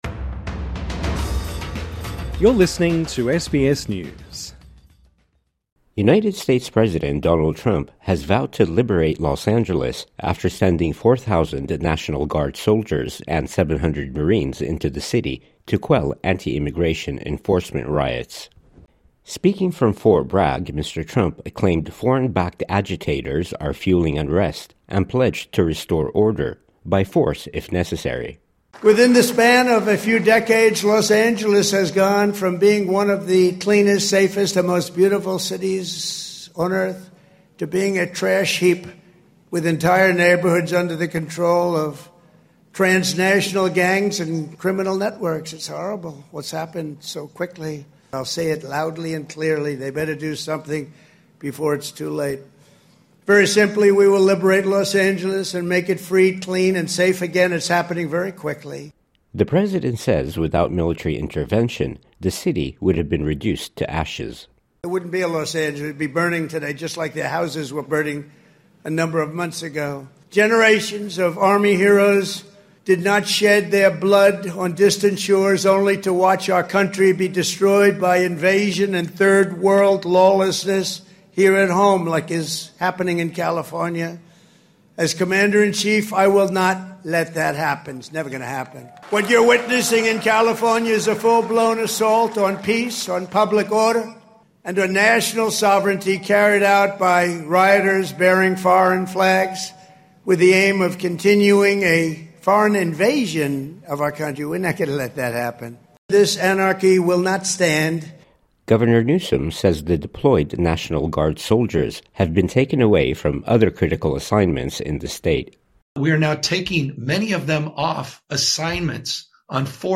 President Donald Trump addresses troops at Fort Bragg amid protests in Los Angeles.